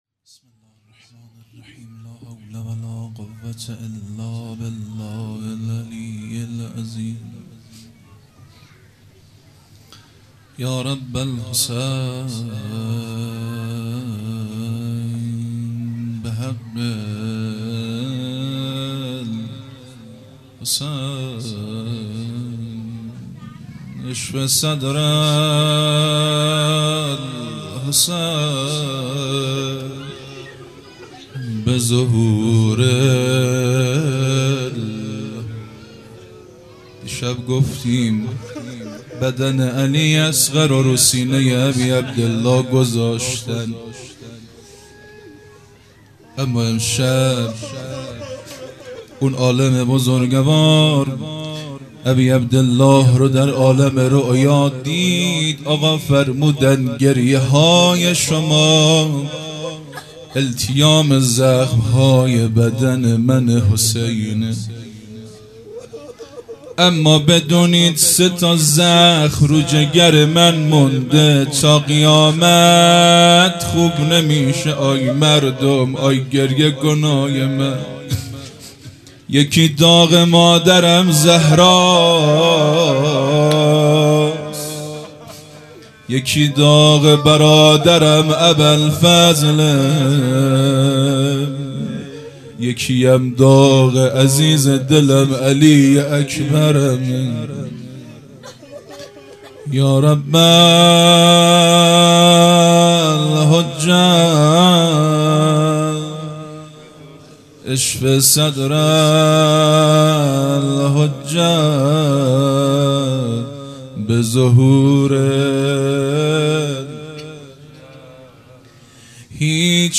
0 0 روضه